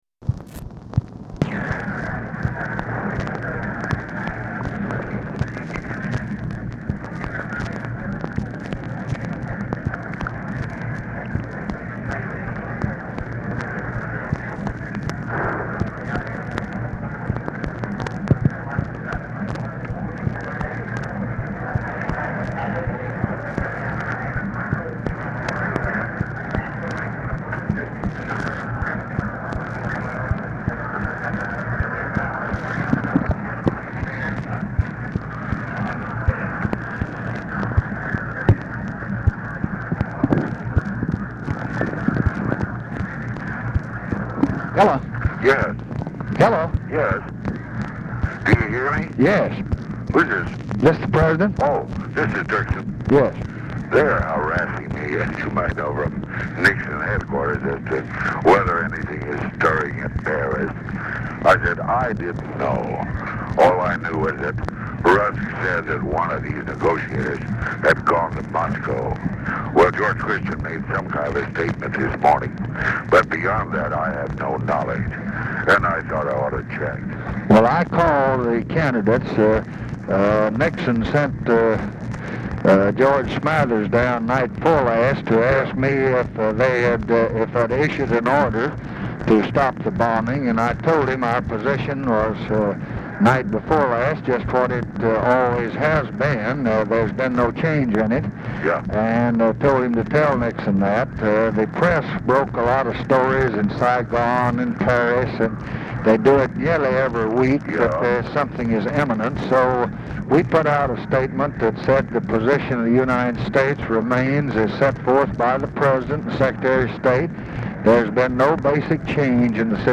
OFFICE NOISE
Oval Office or unknown location
DIRKSEN OH HOLD 0:40; NOISE OF CROWD IS AUDIBLE IN BACKGROUND
Telephone conversation
Dictation belt